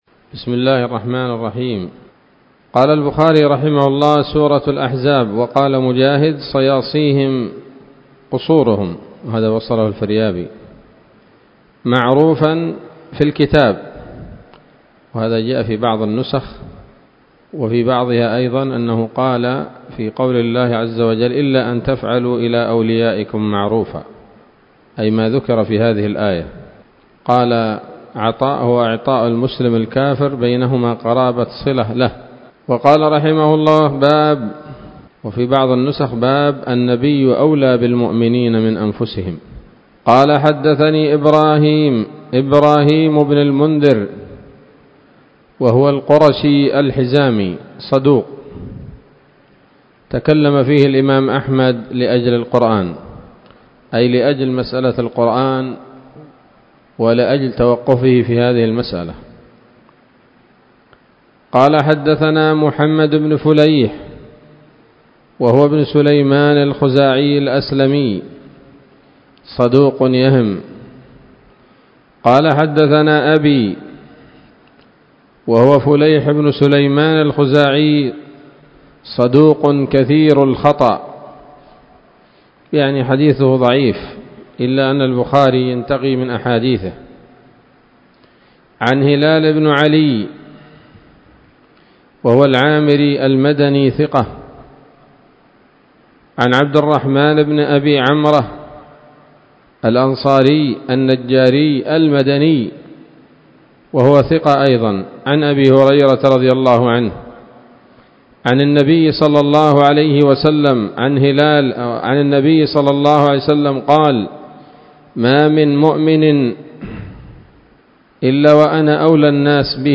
الدرس الأول بعد المائتين من كتاب التفسير من صحيح الإمام البخاري